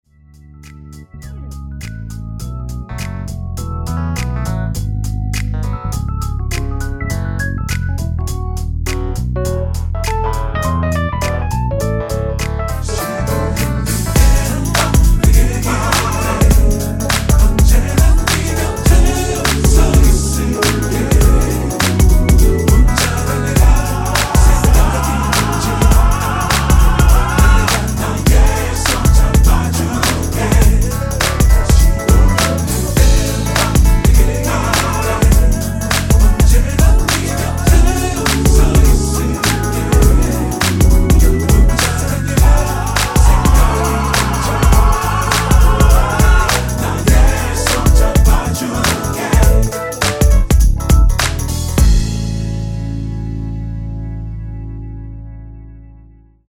MR은 2번만 하고 노래 하기 편하게 엔딩을 만들었습니다.(본문의 가사와 미리듣기 확인)
원키에서(-3)내린 코러스 포함된 MR입니다.
앞부분30초, 뒷부분30초씩 편집해서 올려 드리고 있습니다.